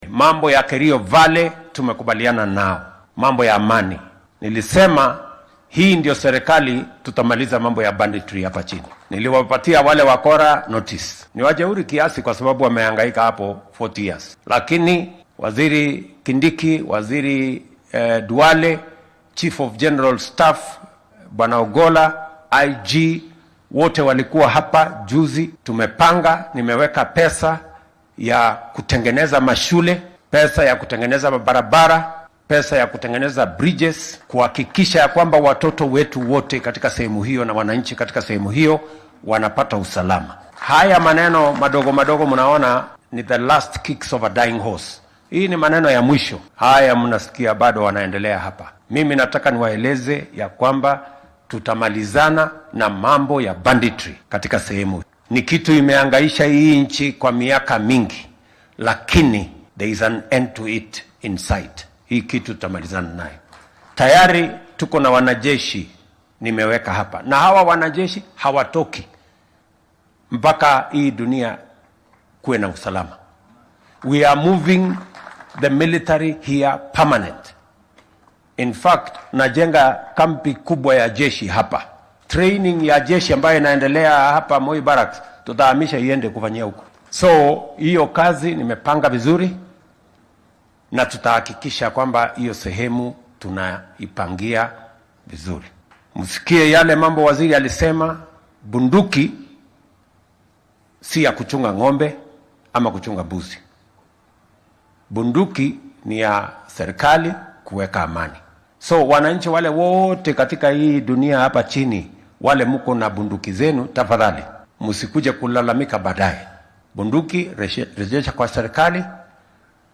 DHAGEYSO:Madaxweynaha dalka oo ka hadlay cirib tirka burcadnimada